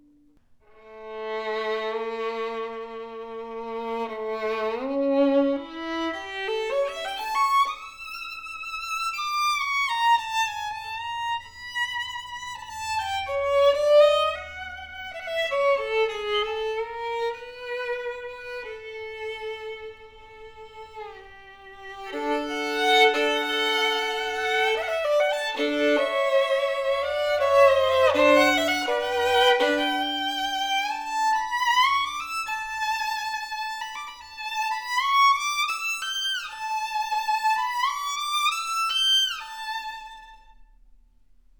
A special edition “Cannone ” violin featuring oil varnish that usually used on our higher end violin, for exquisite antique appearance that also benefits the tone! POWERFULL, dark, projective tone with fantastic projection that carries the tone on distance!
AAA graded seasoned wood that’s plate tuned and graduated for an inviting, antique voice with warm and full projection. Deep ringing G string, great depth and sings with bold dimension. Sweet and focused mid register that speaks with clarity, clean E string with a singing tone quality.